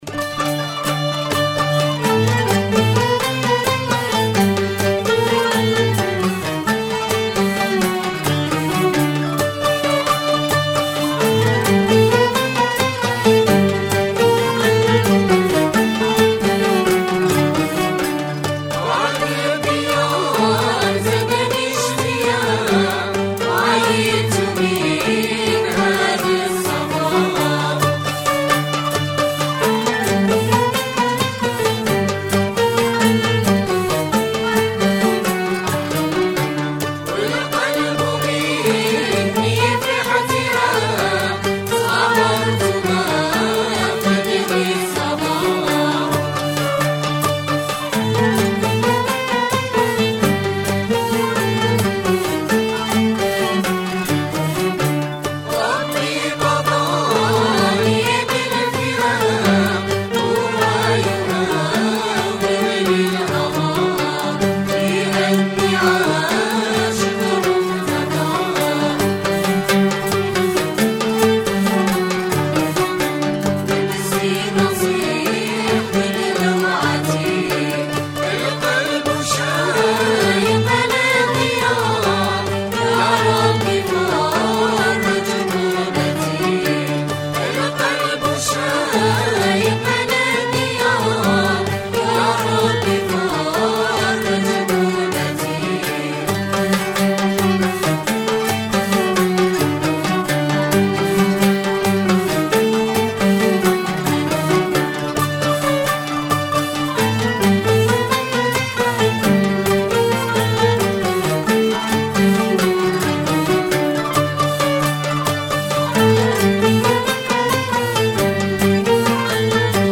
Transcrit sur la base d'un enregistrement de l'association les Annassers de Miliana (dans un programme Malouf).
L'introduction de type Krissi est identique à la réplique des Ghessen (A) (elle-même identique à la ligne du chant, donc non transcrite. Des ornements peuvent s'ajouter.)